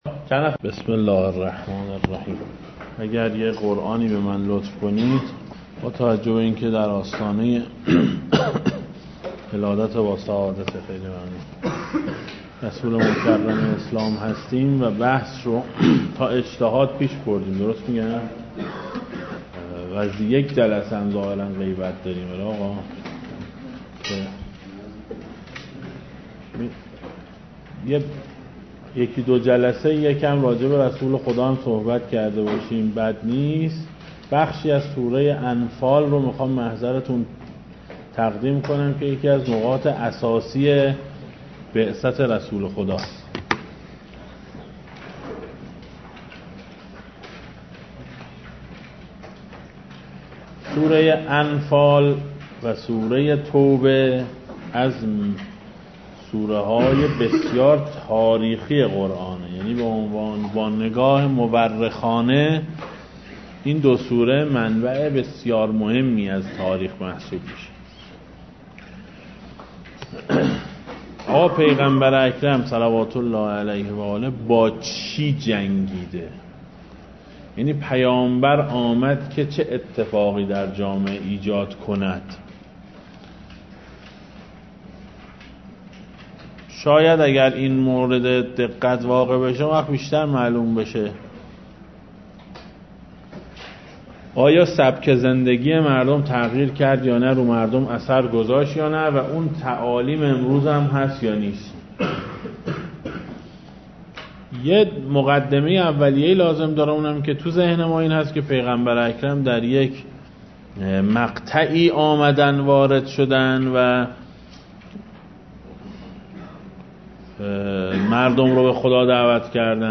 دسته: دروس, سیره اهل بیت علیهم السلام